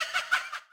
SouthSide Chant (28).wav